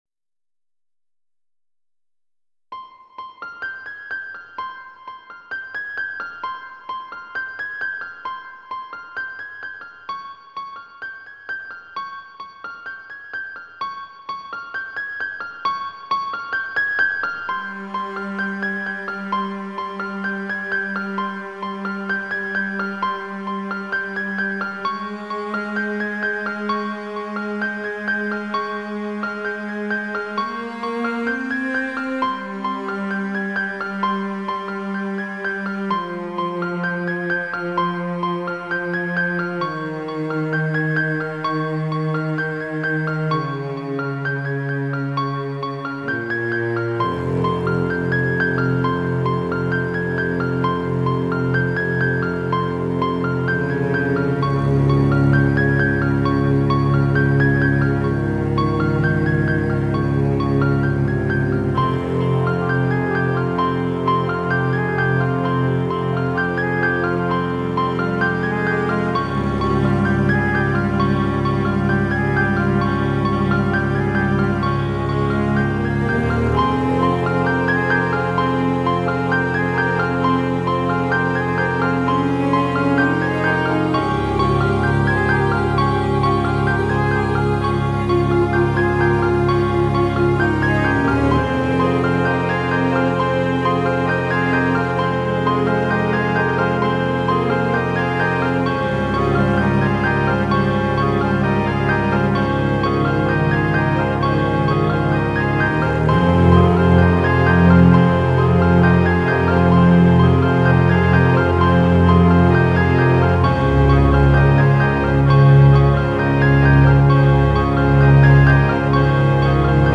instrumental collection